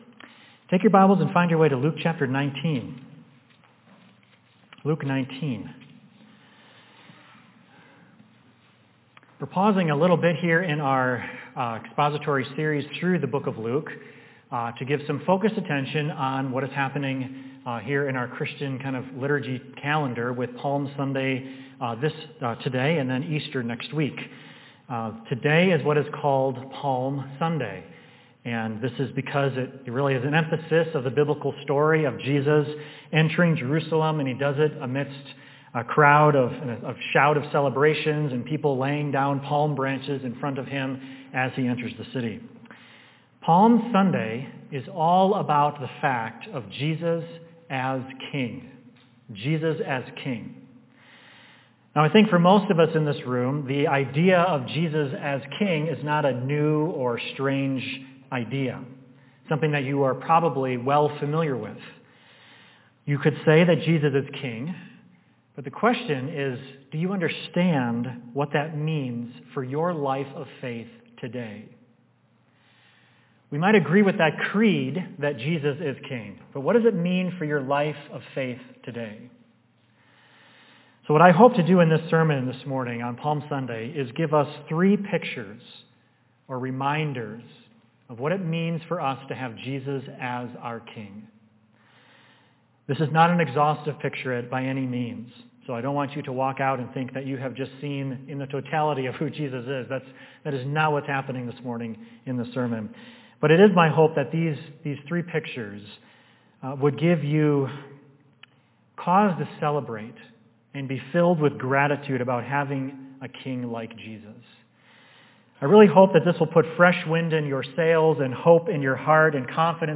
Sermons
Service: Sunday Morning